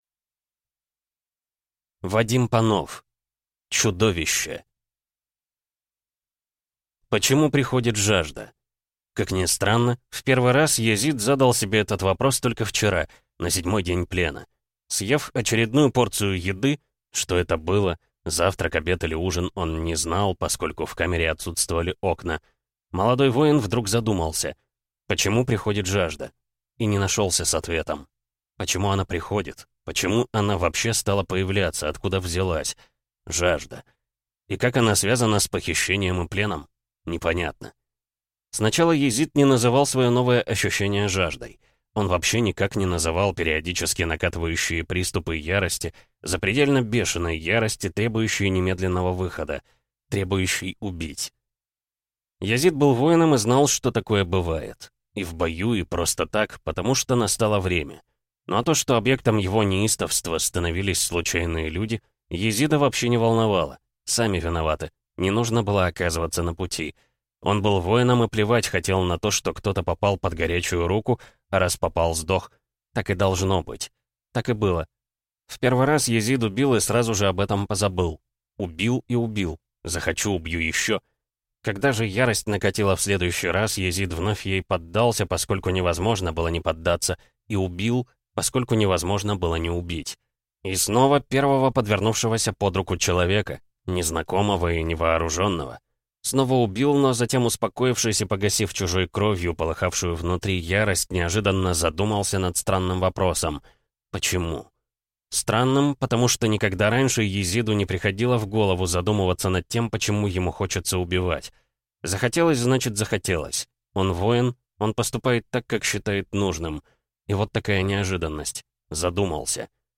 Аудиокнига Чудо(вище) | Библиотека аудиокниг
Прослушать и бесплатно скачать фрагмент аудиокниги